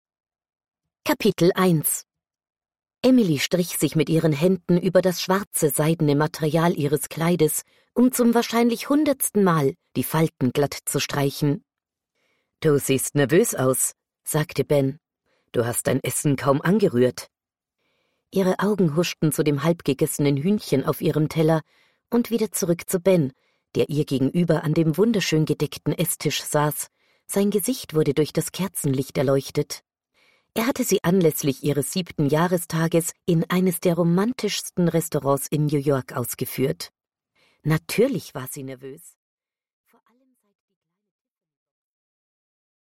Аудиокнига Für Jetzt und Für Immer | Библиотека аудиокниг
Прослушать и бесплатно скачать фрагмент аудиокниги